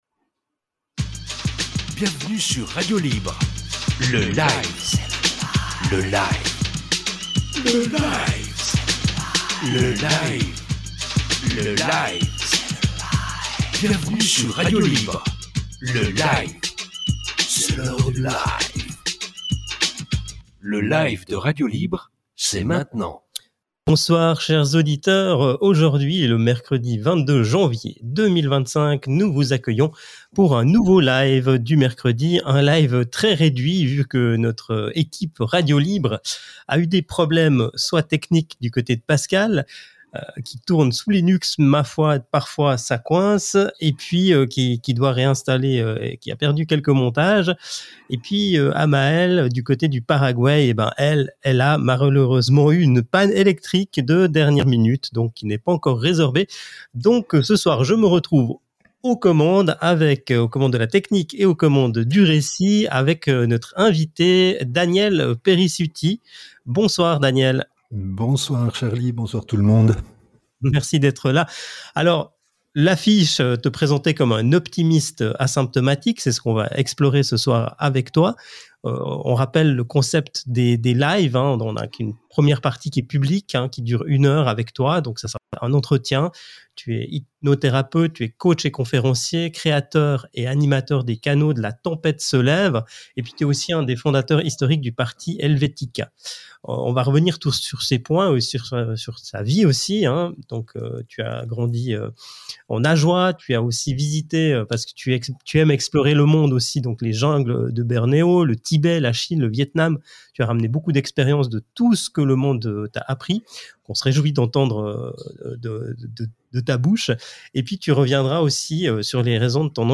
LIVE 22.01.25 à 19h - Radio Libre Suisse
Cette émission fait partie de la série « Entre amis », diffusée en live tous les mercredis 19h ou presque.